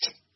tick2.mp3